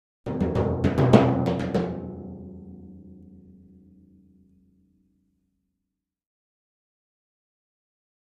Timpani, (Hands), Thin (Pipe) Timpani, Roll Accent, Type 5 - "Timpani Tour